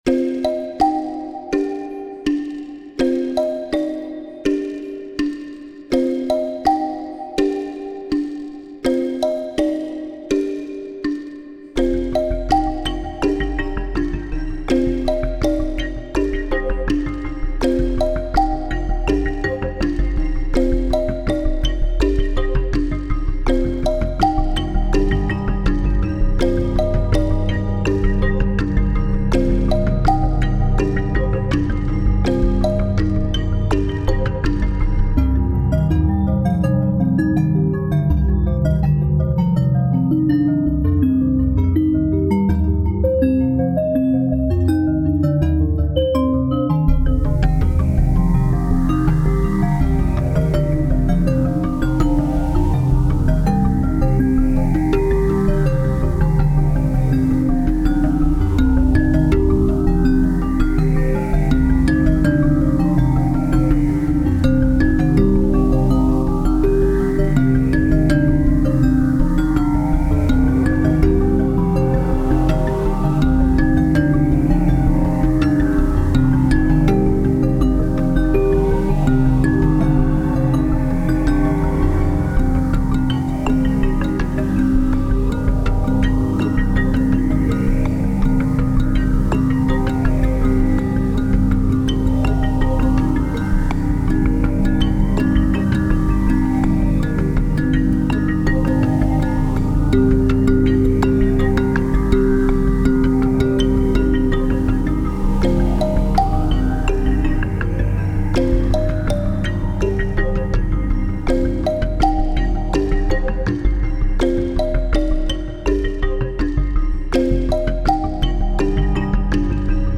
遅めのテンポで音数は少なめ、低音が効いたパッドシンセと残響が広い洞窟の不気味な雰囲気を出している。
タグ: フィールド楽曲 不思議/ミステリアス 不気味/奇妙 冒険 地下/洞窟 コメント: 洞窟をイメージした楽曲。